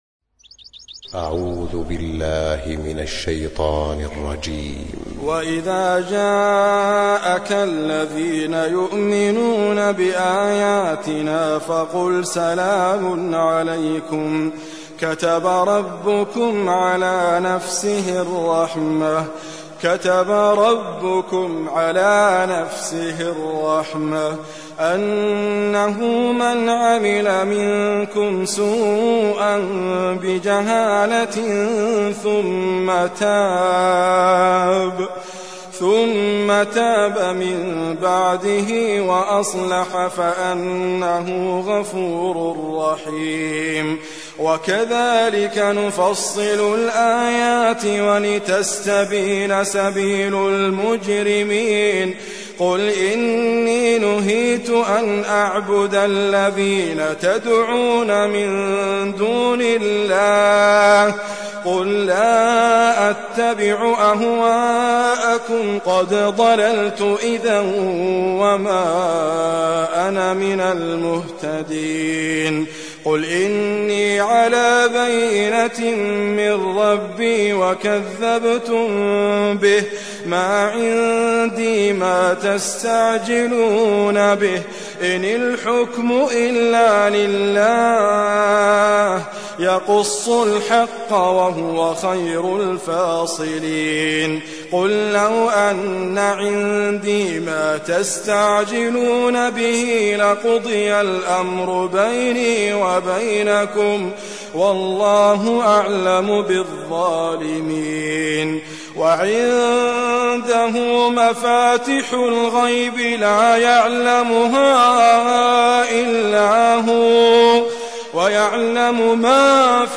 Recitime
Idriss Abkar